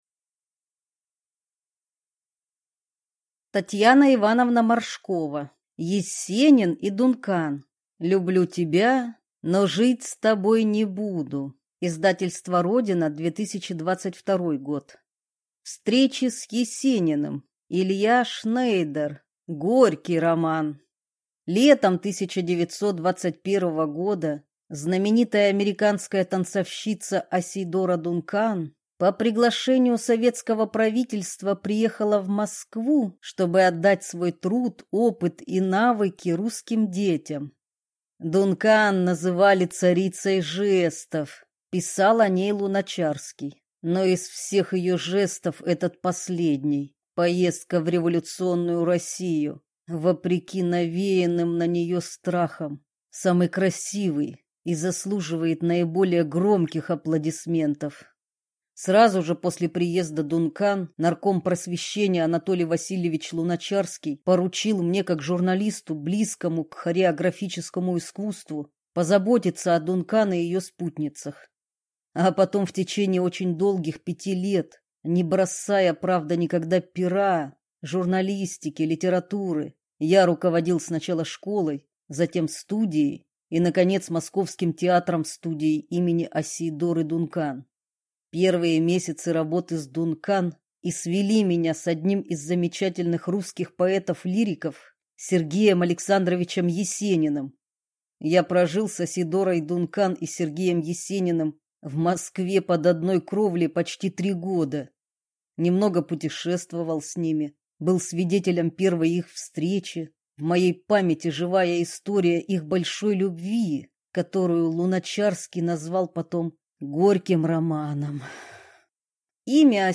Аудиокнига Есенин и Дункан. Люблю тебя, но жить с тобой не буду | Библиотека аудиокниг
Прослушать и бесплатно скачать фрагмент аудиокниги